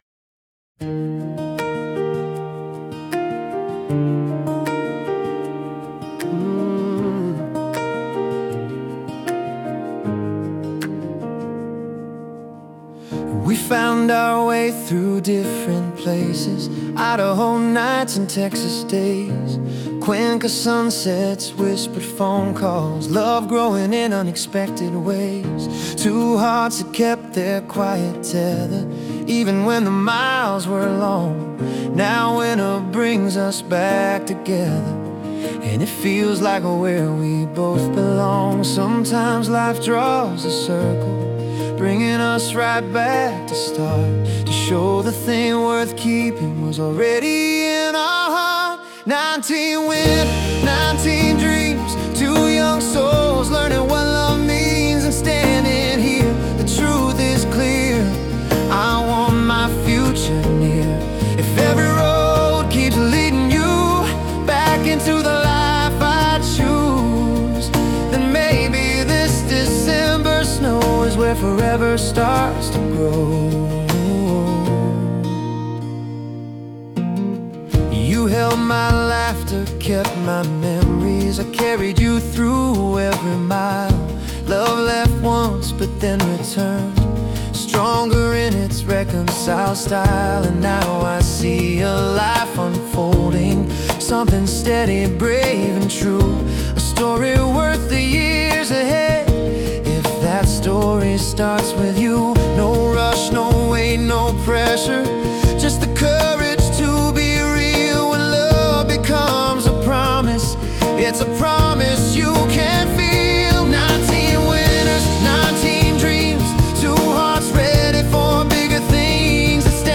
Discover the Heartbeat of Acoustic Storytelling